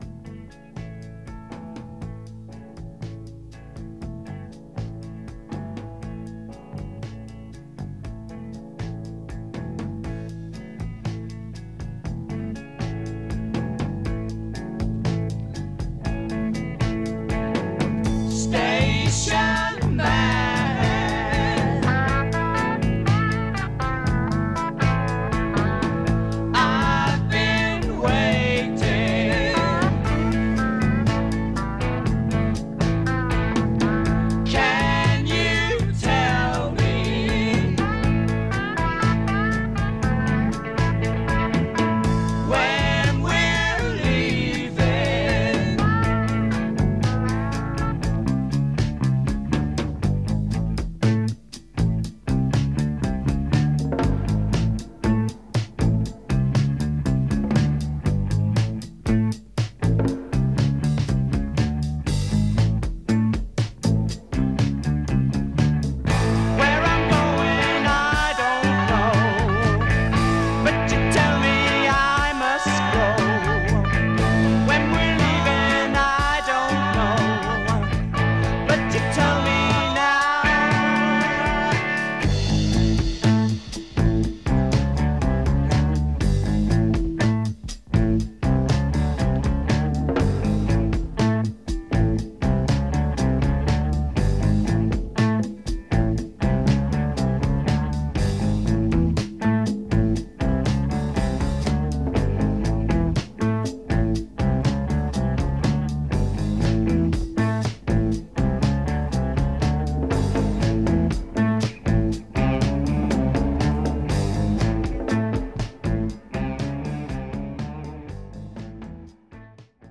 BPM59-60
Audio QualityMusic Cut